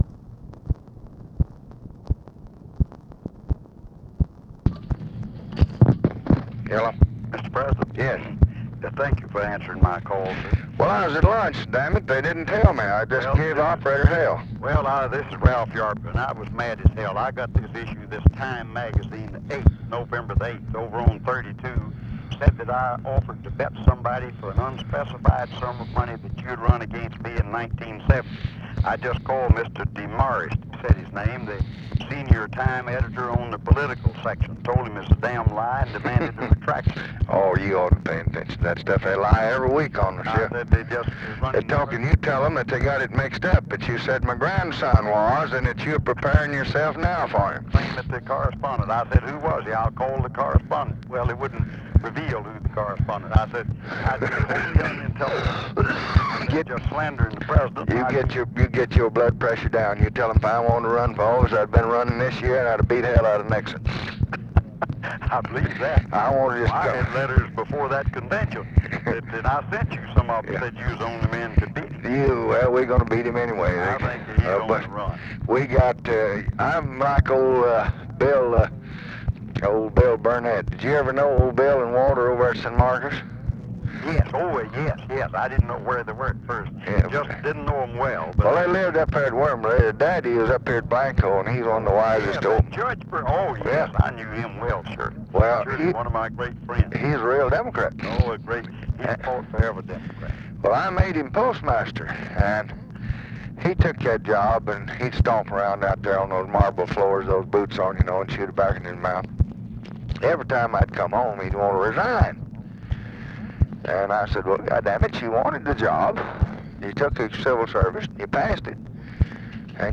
Conversation with RALPH YARBOROUGH, November 4, 1968
Secret White House Tapes